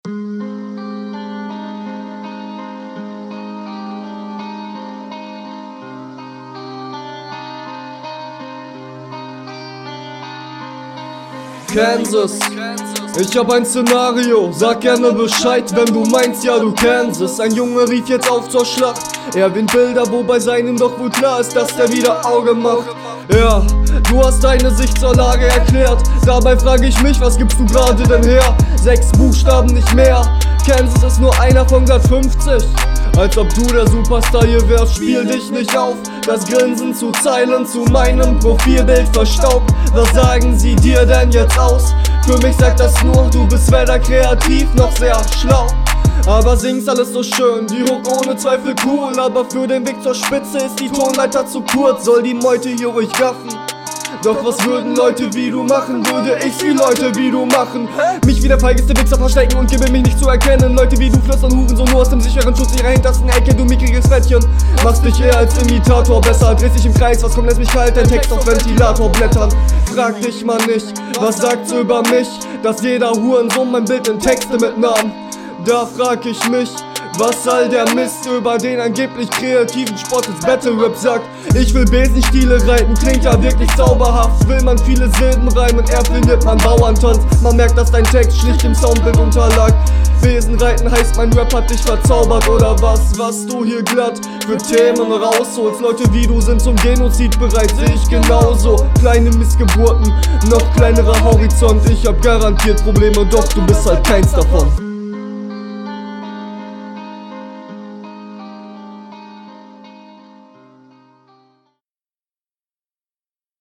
mix ist bei dir schonmal bisi schwächer. da muss ich manche passagen mehrmals hören um …